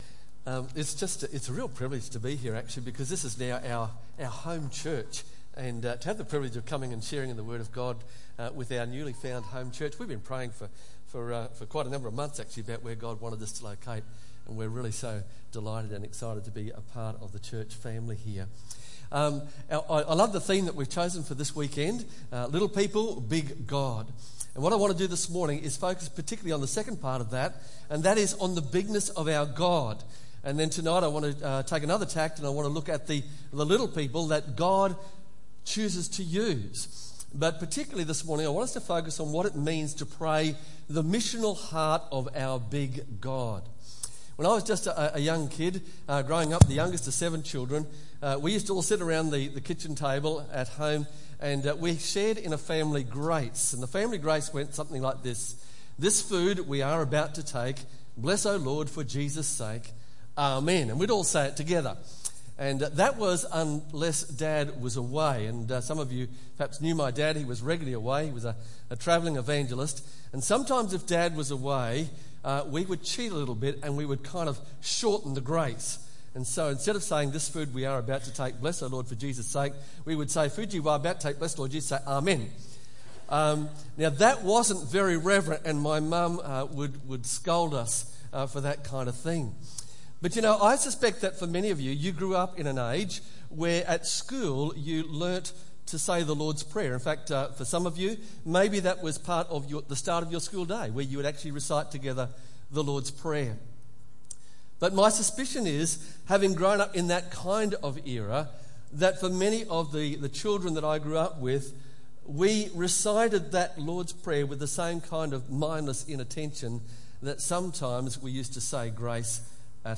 Guest Speaker
Matthew 6:9-14 Tagged with Sunday Morning